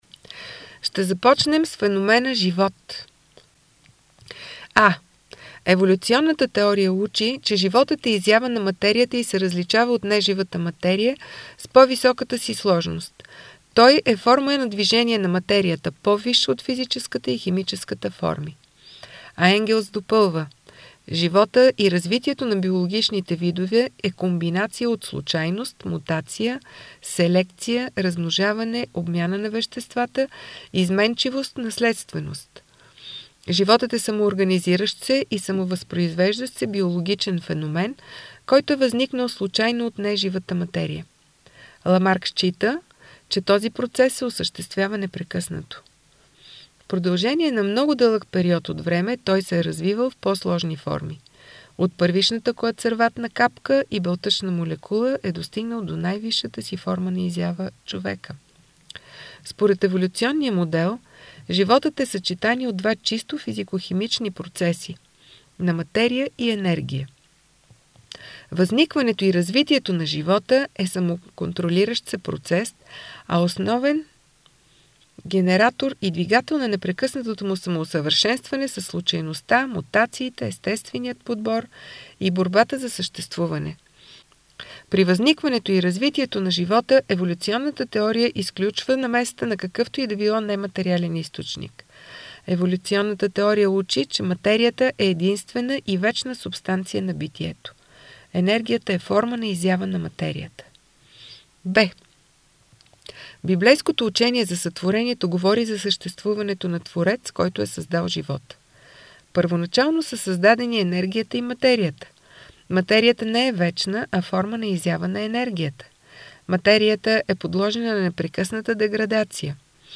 прочит на книгата на проф. д-р Дечко Свиленов, доктор на биологическите науки